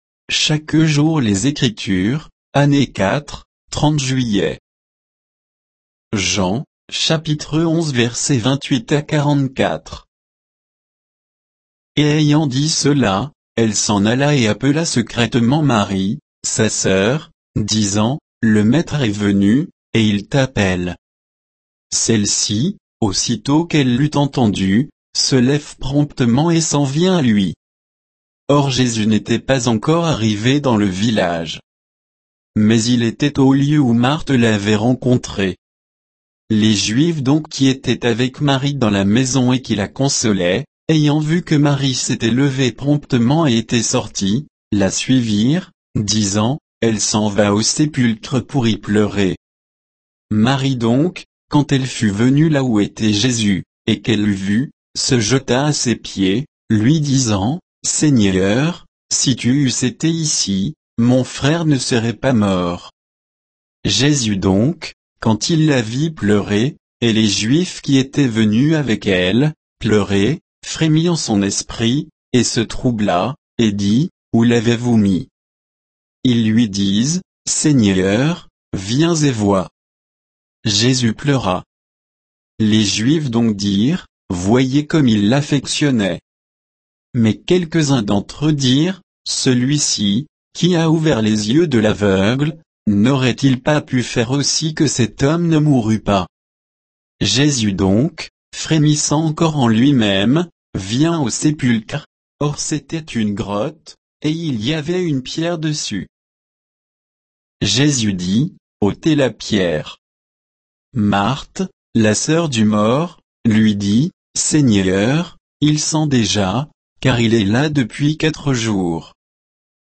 Méditation quoditienne de Chaque jour les Écritures sur Jean 11, 28 à 44